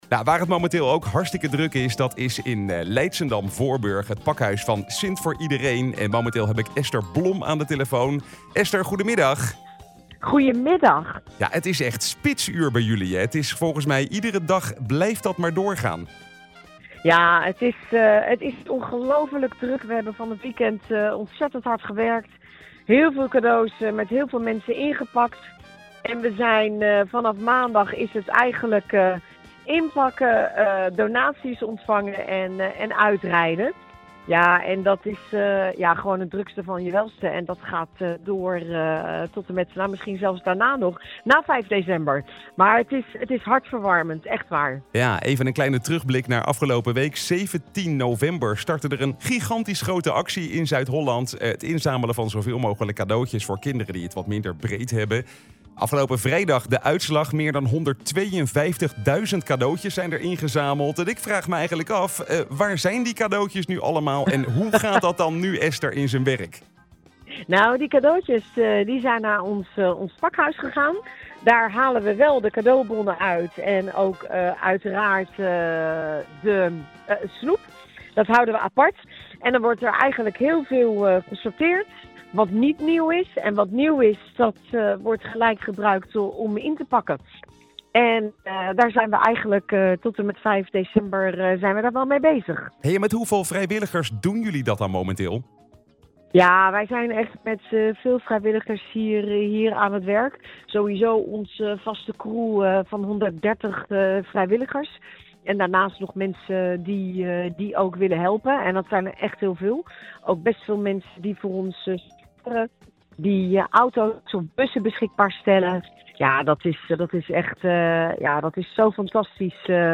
Interview Leiden Leiderdorp Leidschendam-Voorburg Maatschappij Nieuws Oegstgeest Voorschoten Wassenaar Zoeterwoude